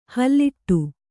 ♪ halliṭṭu